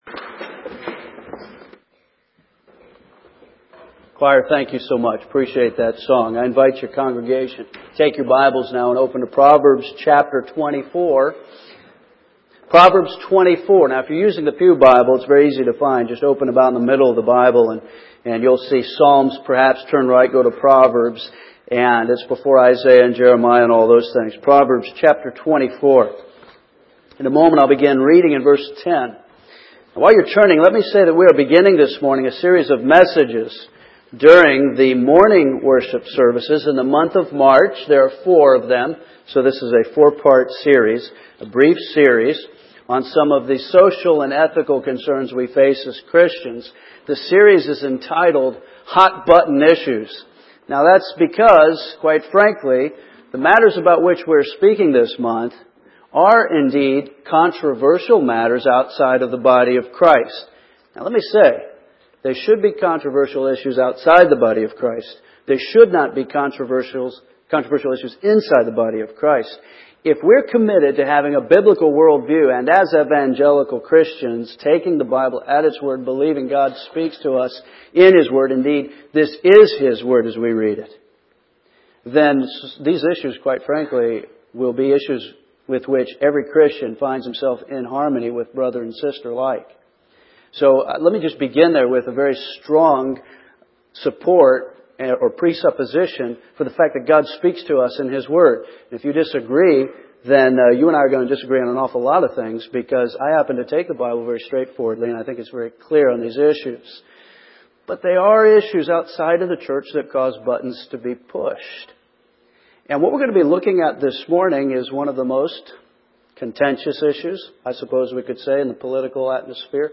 This morning we are beginning a short series of messages during the morning worship services in the month of March, a four-part series on social and ethical concerns called, “Hot-Button Issues.”